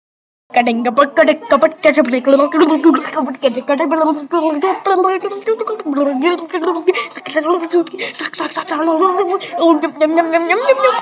Random Ahh Sounds Botão de Som